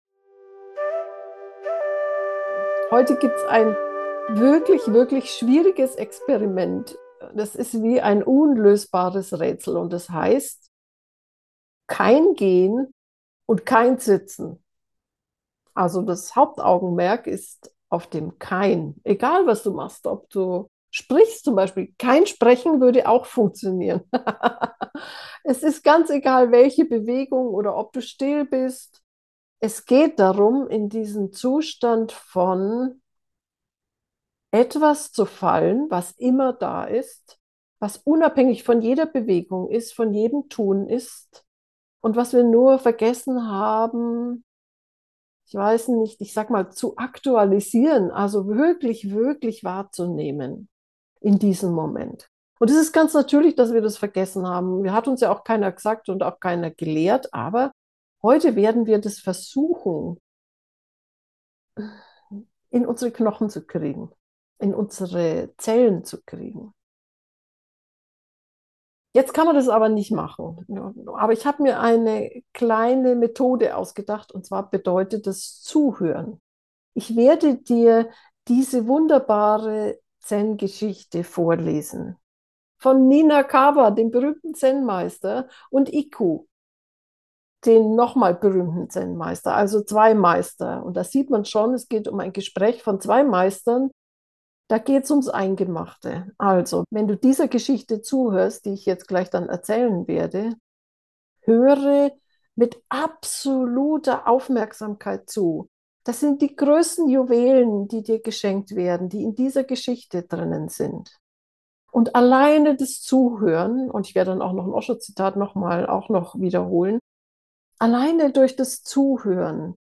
Geführte Meditationen Folge 239: Kein Kommen – kein Gehen.